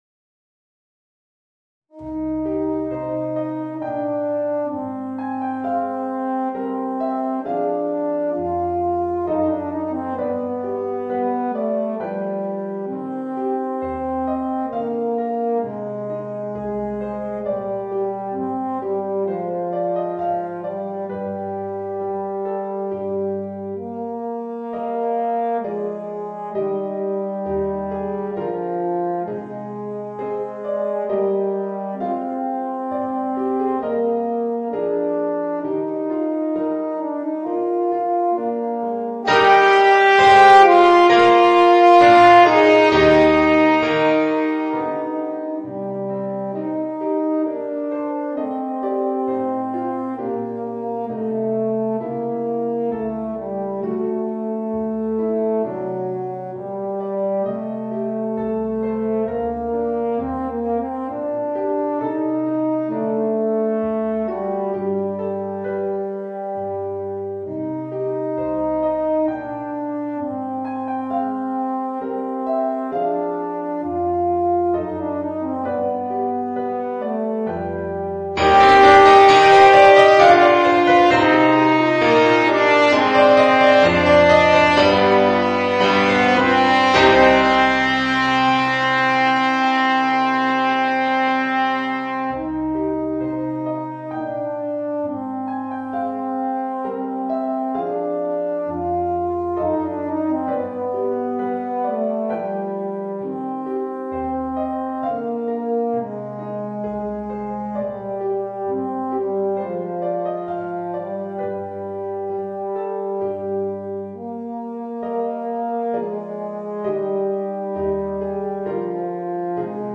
Voicing: Eb Horn and Organ